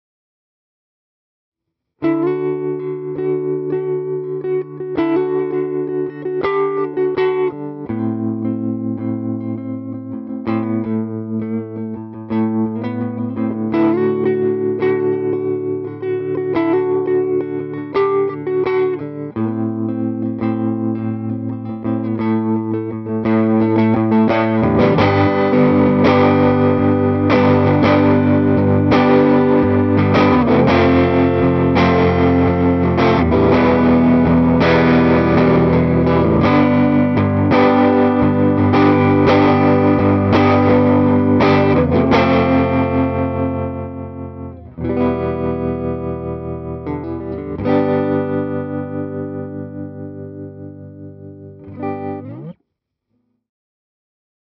In this example the amplifier is used for some relaxed playing with slight overdrive on the first half and quite distorted in the second part (by the “2nd channel” trick with the pedal):